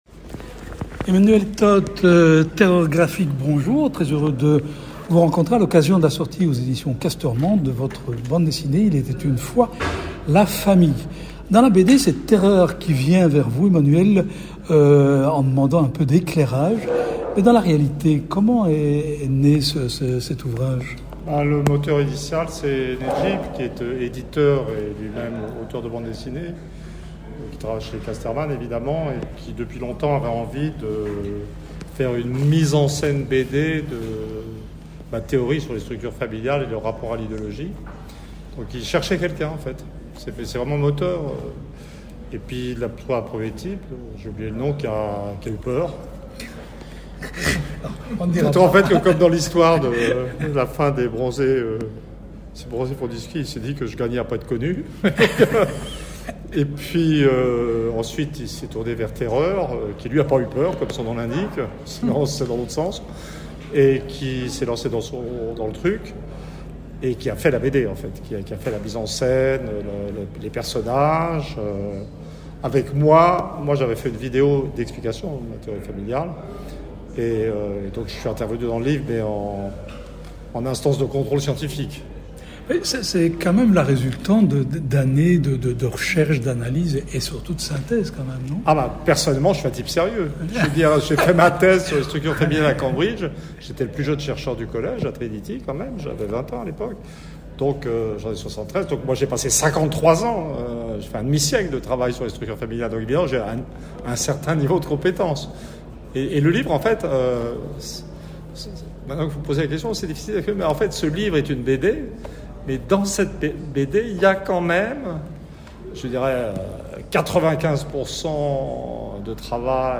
Rencontre. On y parle de Clint, des Clash, de Poutine, du Swinging London, de marmelade, de vulgarisation, de chat, de fin de cycle, d’esprit de synthèse, de Dutronc, de Playboys, de jeux vidéo…